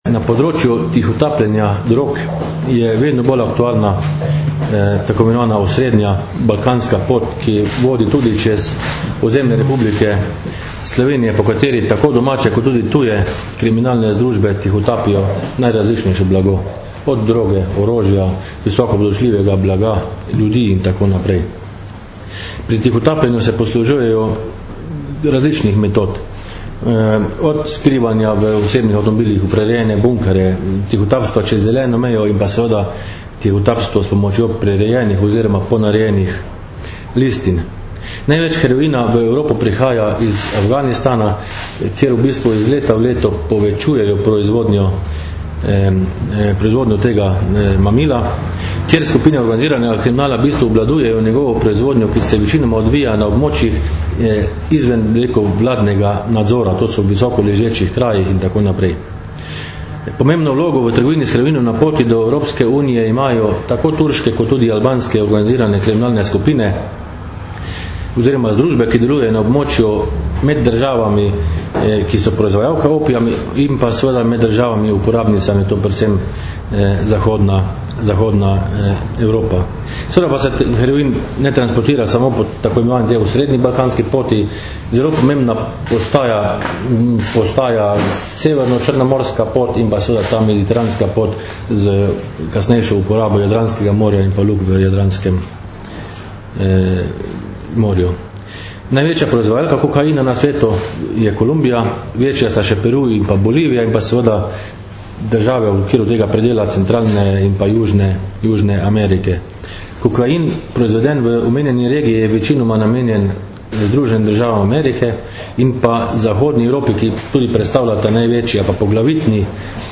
Policija - Zaradi tihotapljenja prepovedanih drog policisti ovadili 21 oseb in razkrili delovanje več kriminalnih združb - informacija z novinarske konference
Zvočni posnetek izjave